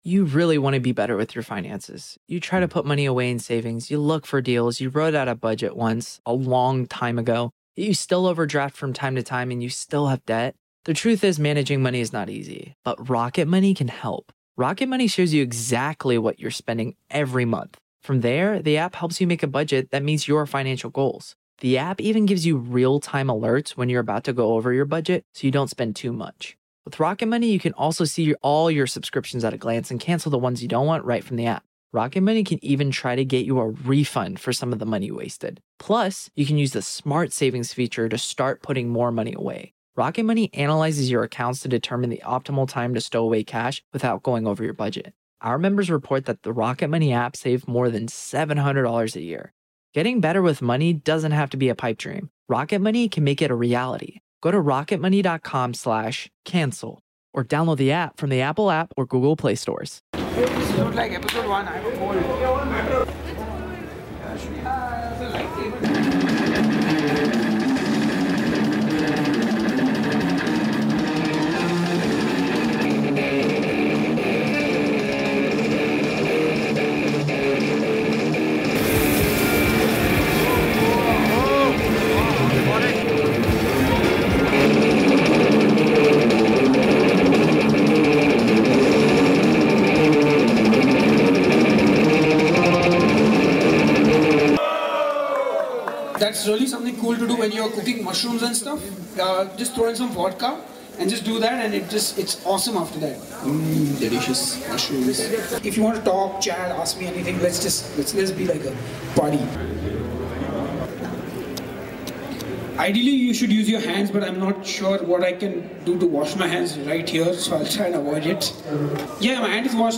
Headbanger's Kitchen - Live in Bangalore @ The Big Junction Jam